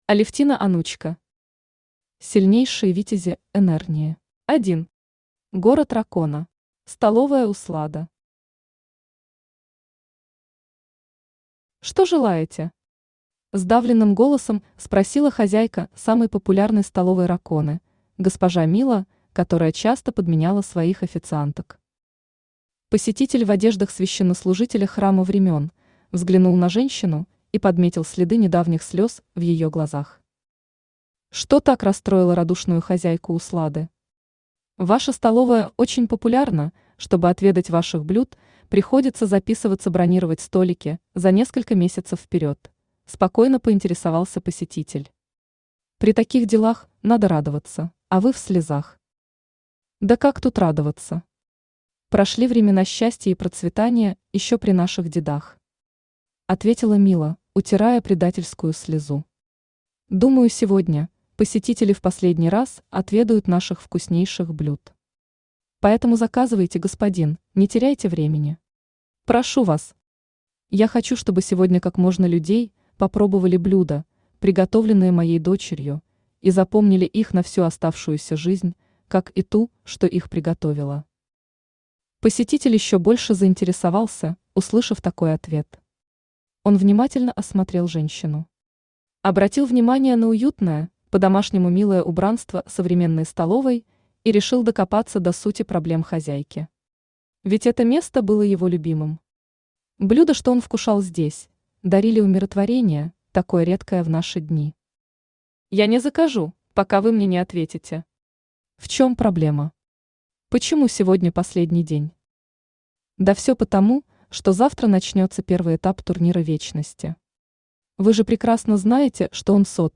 Аудиокнига Сильнейшие витязи Энэрнии | Библиотека аудиокниг
Aудиокнига Сильнейшие витязи Энэрнии Автор Алевтина Александровна Онучка Читает аудиокнигу Авточтец ЛитРес.